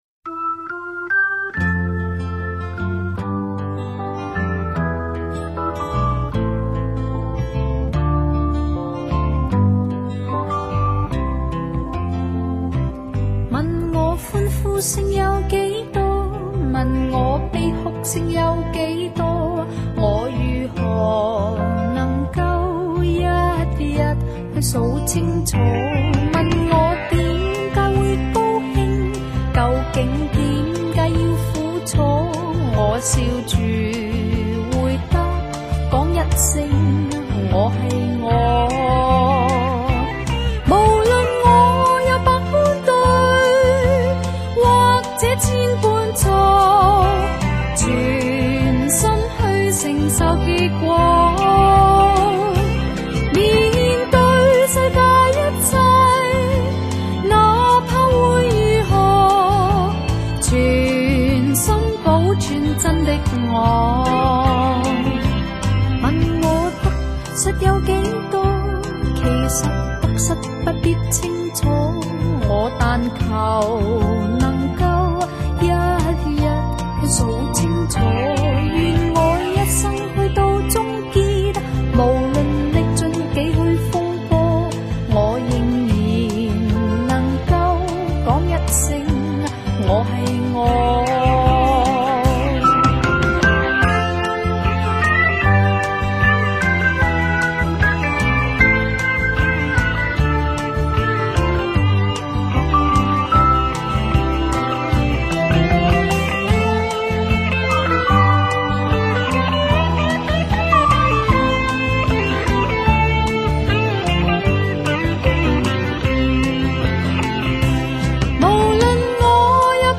但旋律不显得很古董